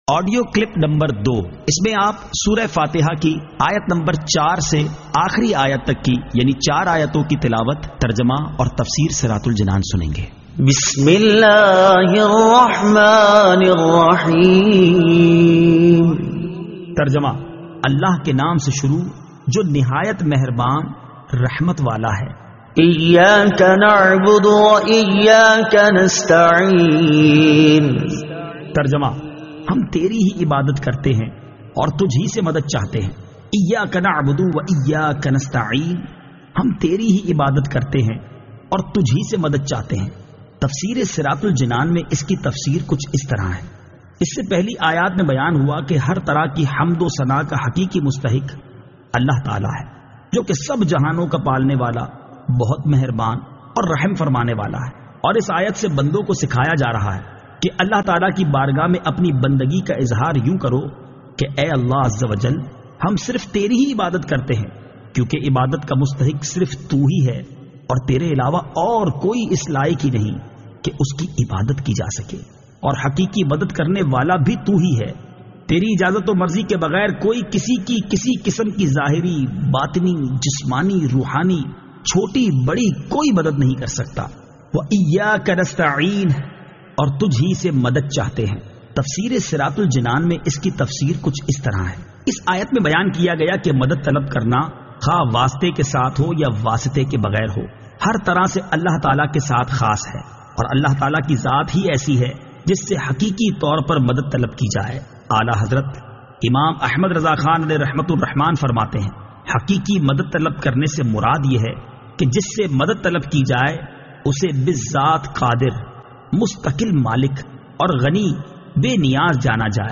Surah Al-Fatiha Ayat 04 To 07 Tilawat , Tarjama , Tafseer